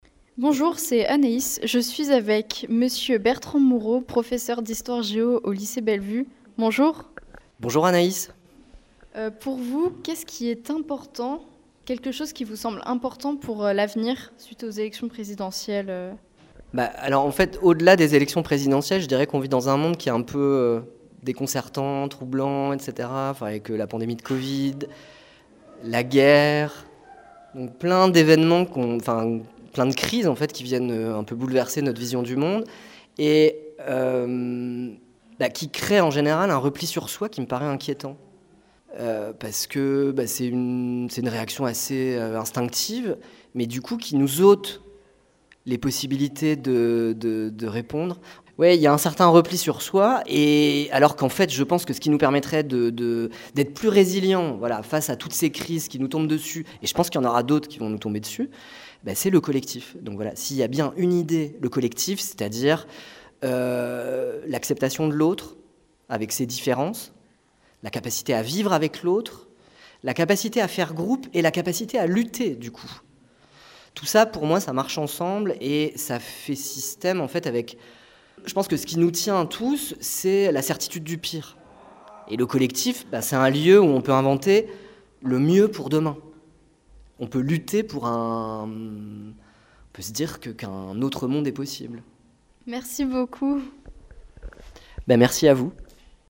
Micro-trottoir